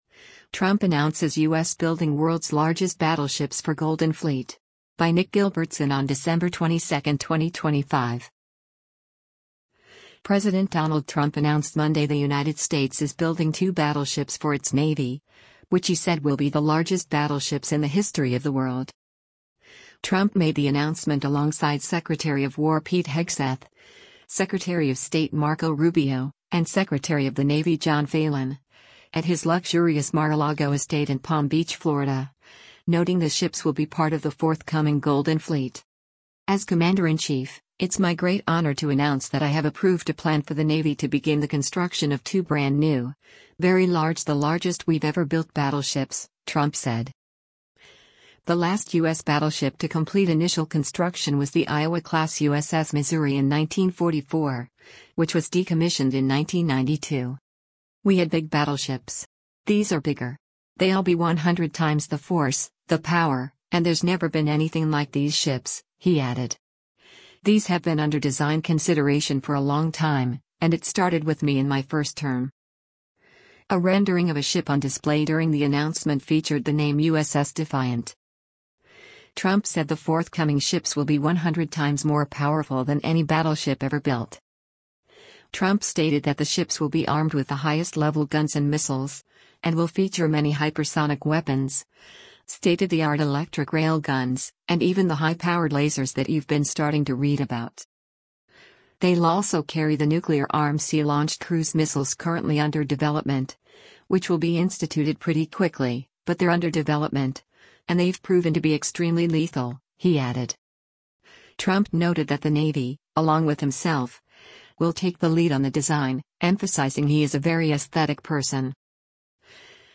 Trump made the announcement alongside Secretary of War Pete Hegseth, Secretary of State Marco Rubio, and Secretary of the Navy John Phalen, at his luxurious Mar-a-Lago estate in Palm Beach, Florida, noting the ships will be part of the forthcoming “Golden Fleet.”